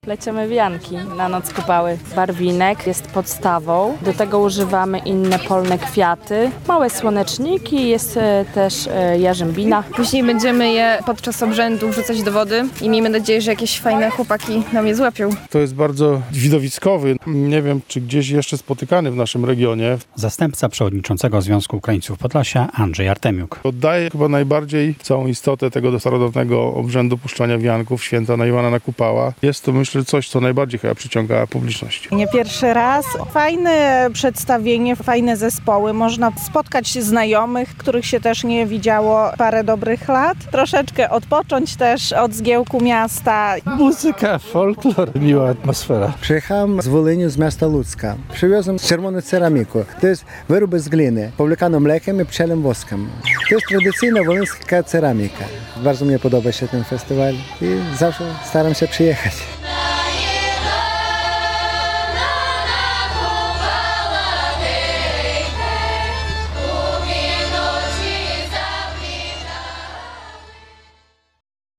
Festiwal "Na Iwana, na Kupała" w Dubiczach Cerkiewnych - wianki na wodzie i występy folklorystycznych zespołów [zdjęcia]
W Dubiczach Cerkiewnych odbyła się ukraińska kupalska noc "Na Iwana, na Kupała".